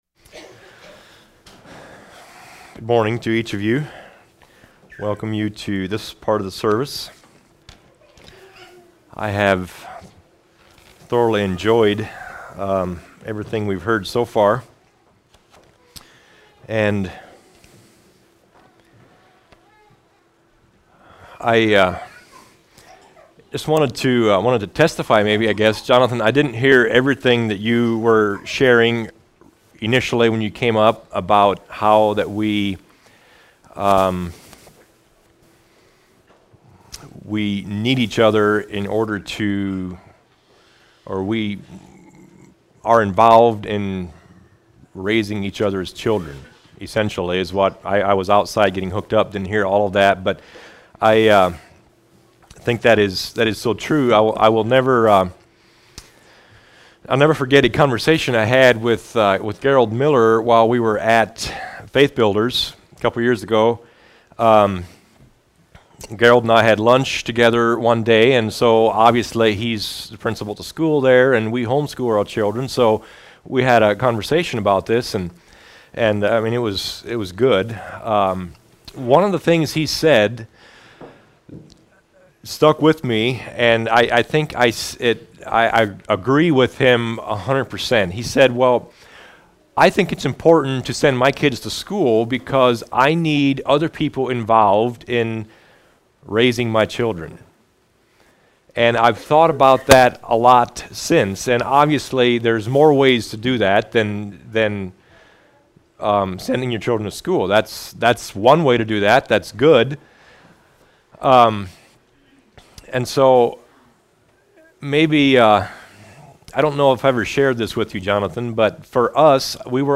Home Sermons What Am I To Do?